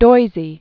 (doizē), Edward Adelbert 1893-1986.